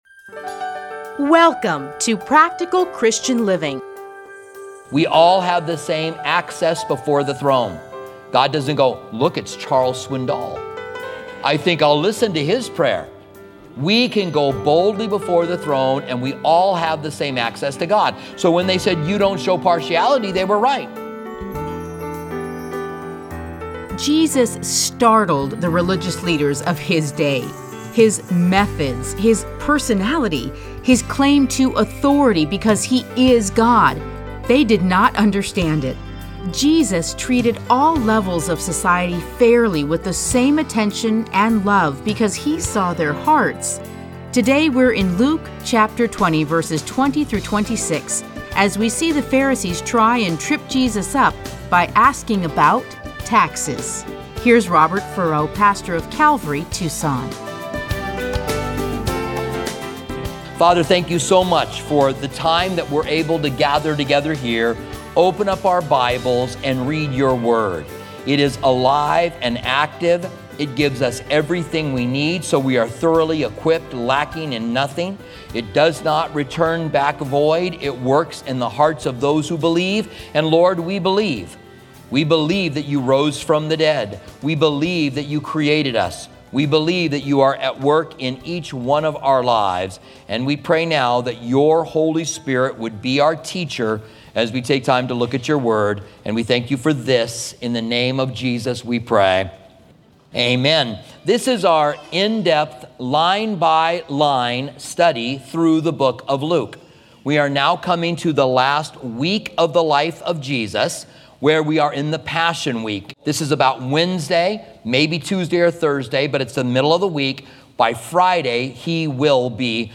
Listen to a teaching from Luke 20:20-26.